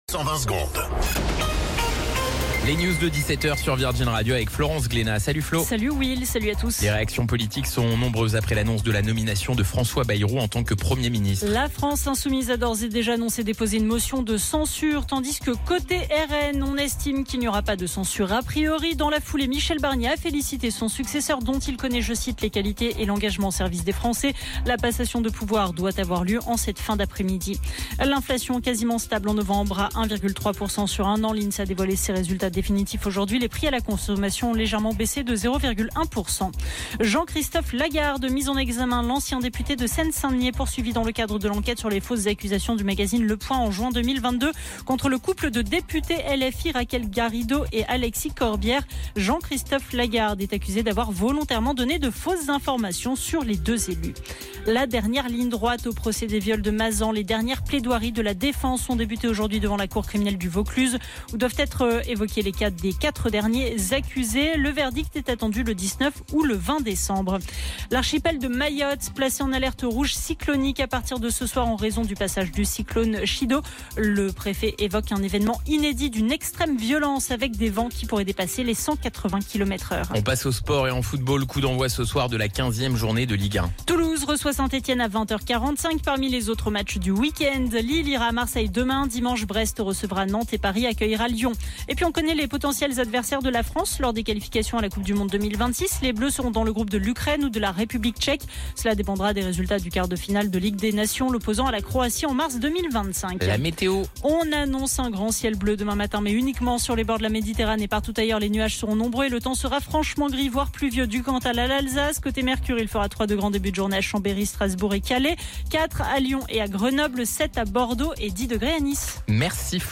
Flash Info National 13 Décembre 2024 Du 13/12/2024 à 17h10 .